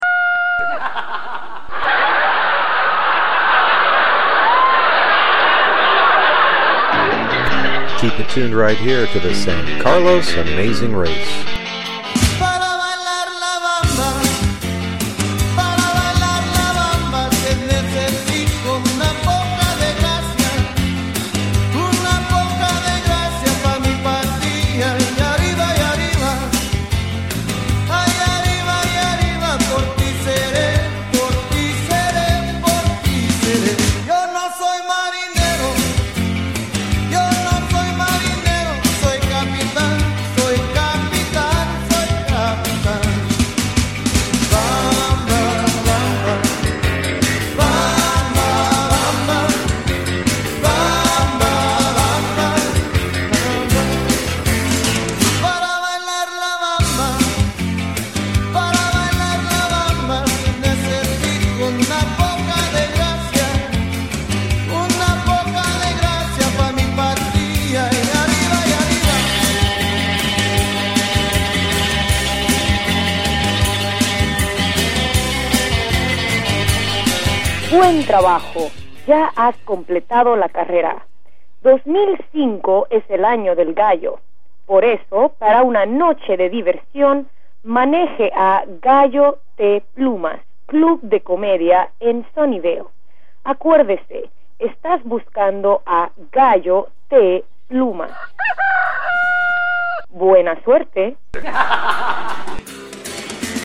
The final clue was an audio clue . Teams were given a CD with music and audio files designed to lead them to Rooster T. Feathers.